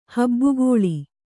♪ habbu gōḷi